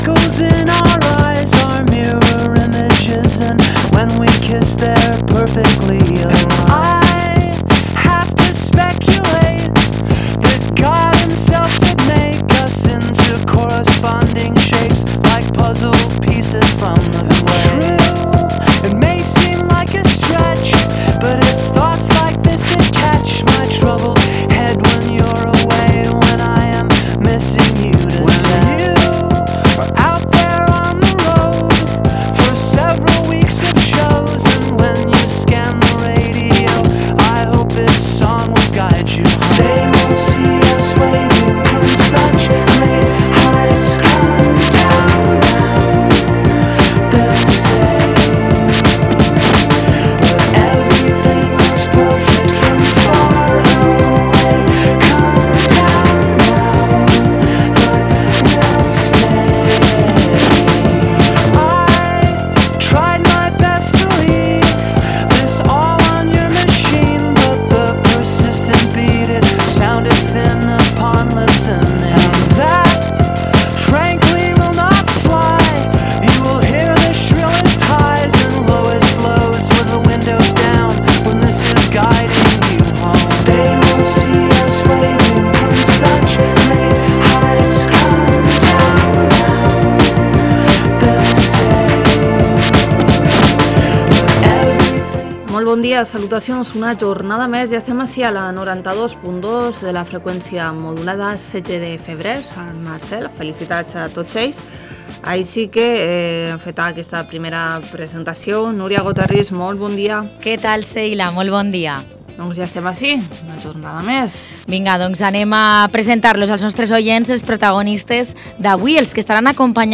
Un miércoles más, hemos recibido a José Benlloch para que responda las preguntas de nuestros oyentes en «L’alcalde t’escolta».